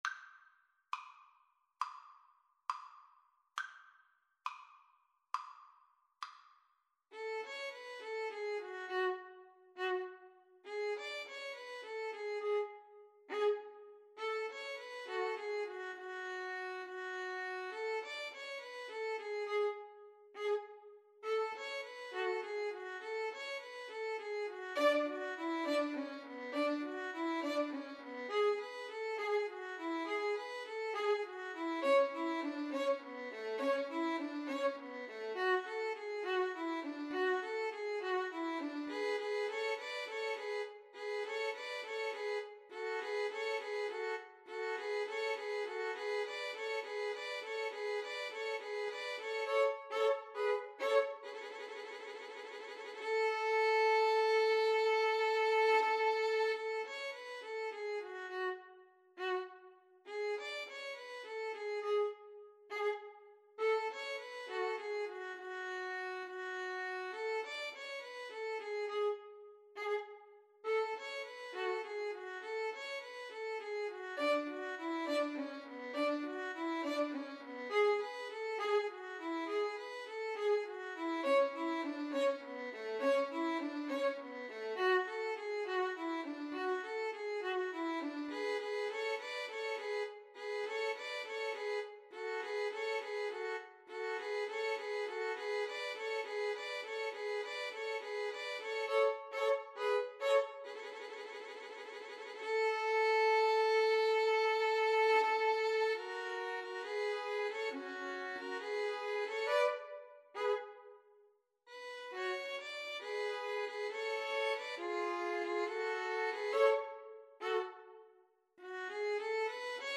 Violin 1Violin 2Violin 3
Andante = c. 68
4/4 (View more 4/4 Music)
Classical (View more Classical Violin Trio Music)